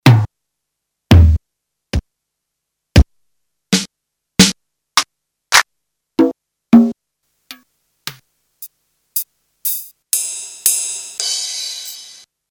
PCM digital drum percussion (1985)
demo pattern1
all sounds single shot
- bassdrum is awful
REVIEW "hard-to-find drum box! the sound is nothing special, enjoyable if you're into those PCM 12 bits sounds or a drum machine collector. Not so recommanded."